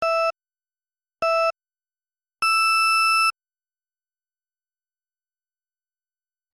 countdown.mp3